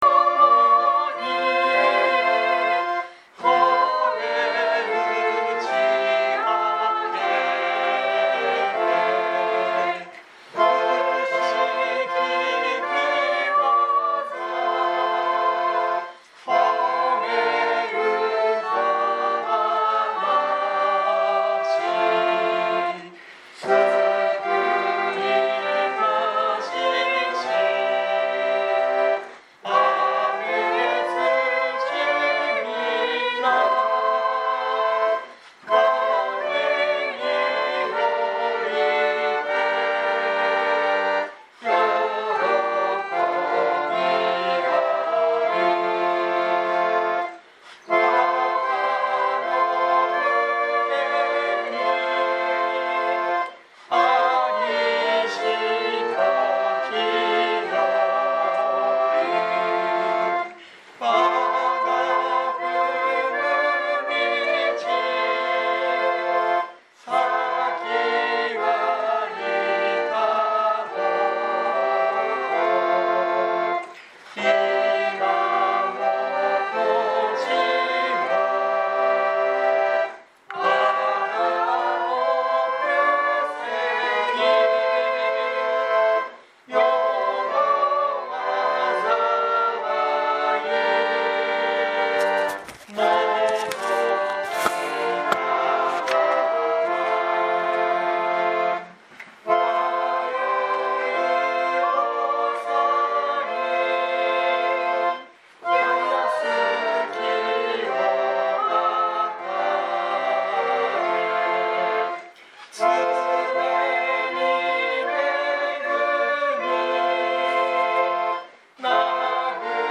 2023年08月27日朝の礼拝「弟子ユダの裏切り」熊本教会
熊本教会。説教アーカイブ。